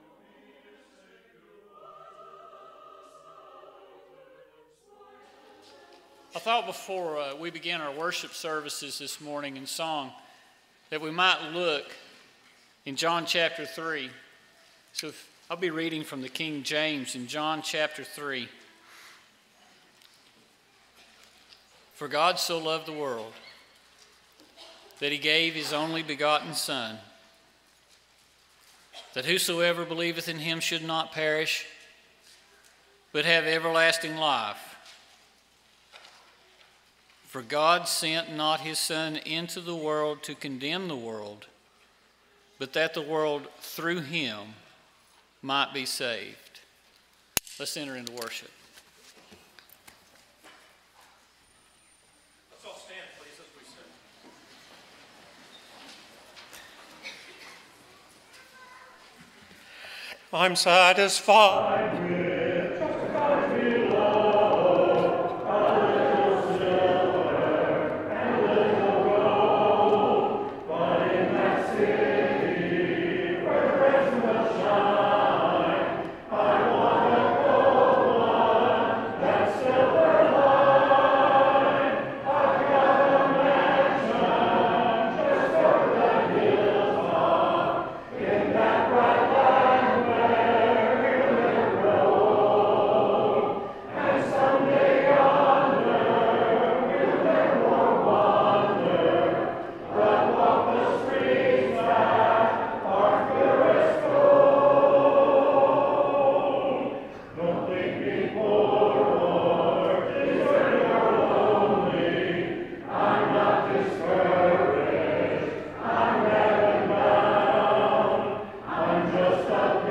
Luke 13:3,5, English Standard Version Series: Sunday AM Service